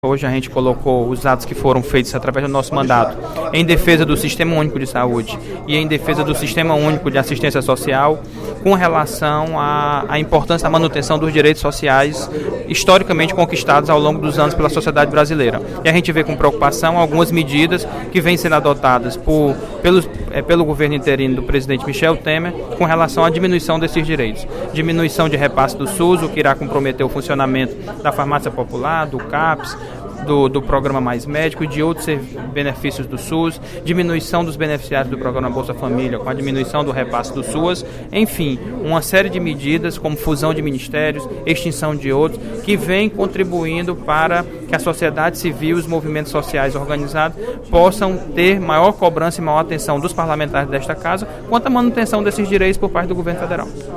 O deputado George Valentim (PCdoB) criticou, durante o primeiro expediente da sessão plenária desta quarta-feira (15/06), algumas medidas adotadas pelo governo interino de Michel Temer.